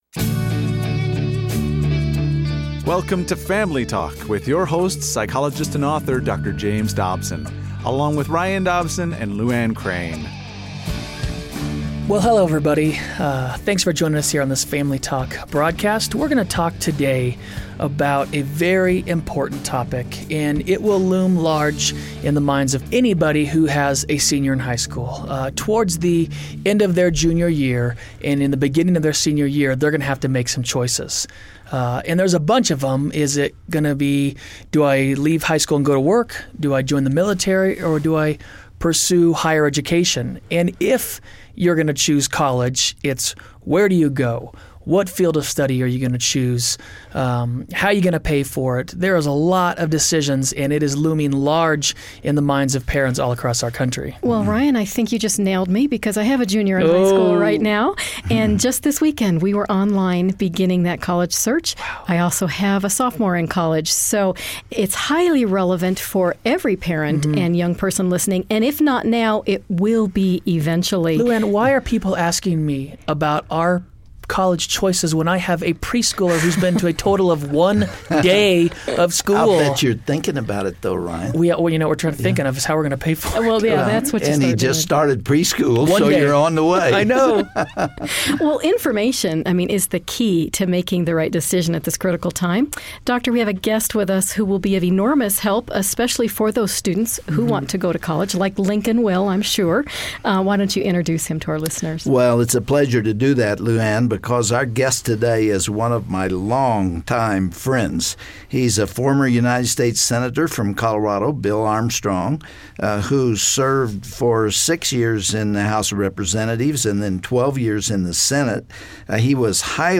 Dr. Dobson is joined in the studio today by College president and former US Senator, Bill Armstrong. Together, they provide clarity and offer practical advice regarding the important decision of where to go to College!
Host Dr. James Dobson